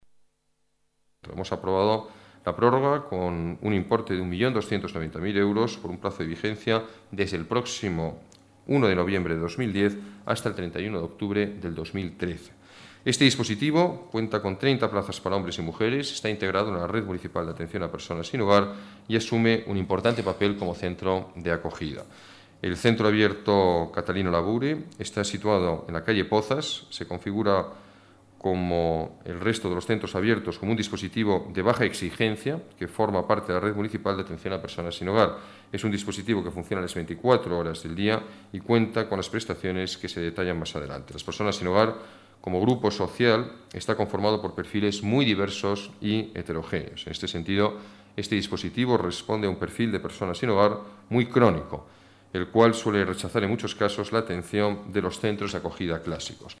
Nueva ventana:Declaraciones del alcalde de Madrid, Alberto Ruiz-Gallardón: Inversiones para el Centro Abierto para personas sin hogar